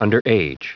Prononciation du mot underage en anglais (fichier audio)
Prononciation du mot : underage